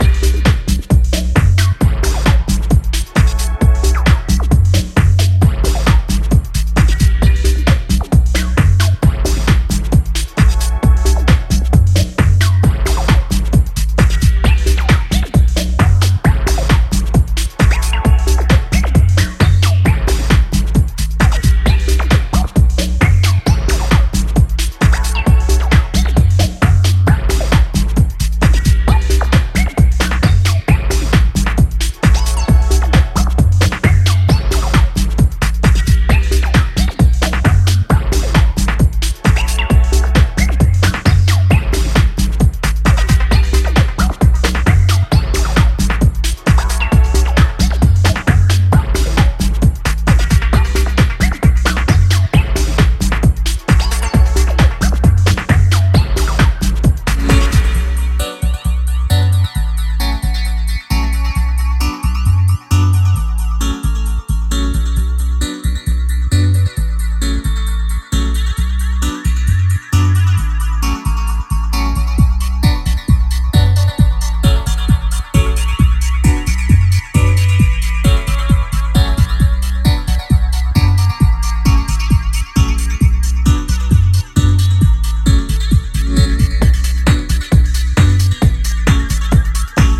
ラガテイストを増したB面の2曲